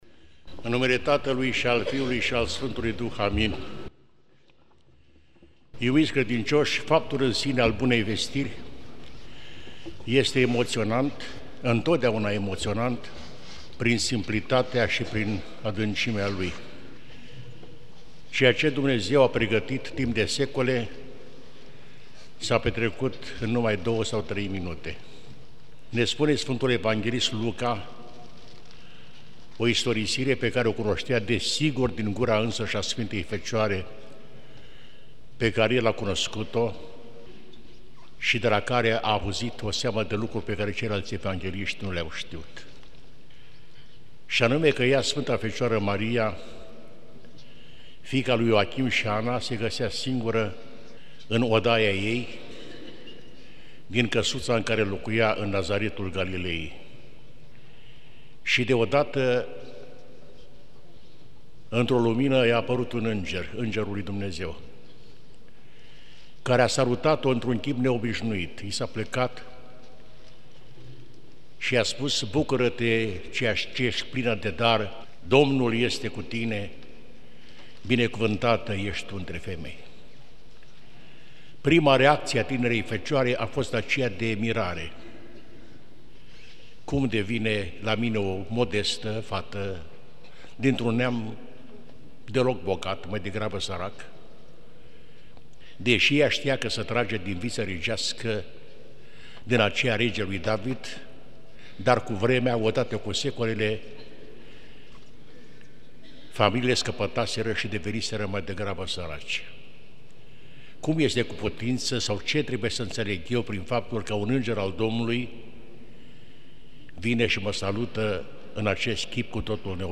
IPS Bartolomeu Anania - Predica la Bunavestire - 25 martie_l43_RO.mp3